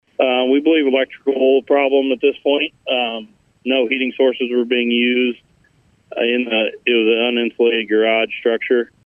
The Chief speculated on a cause of the fire.